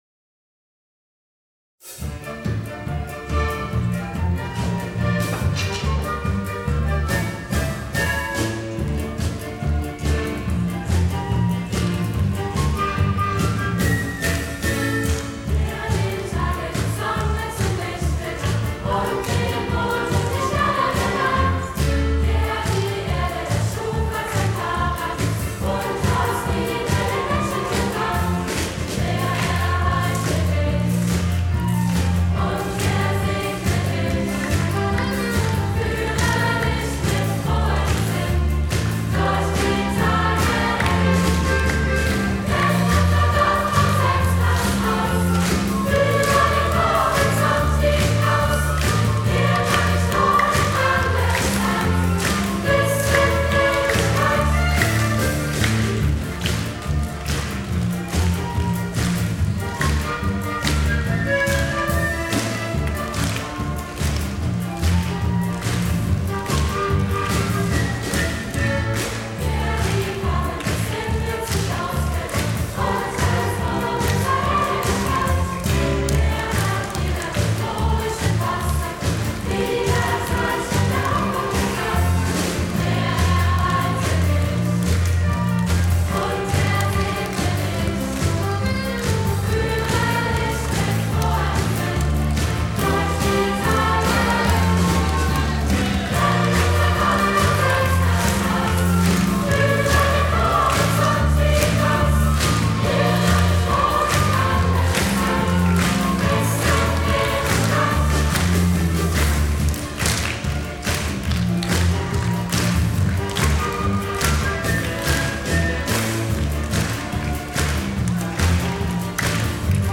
Gemeindelied
Klavierpartitur und Chorausgabe